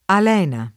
al$na]: Amando Alena tanto dolzemente [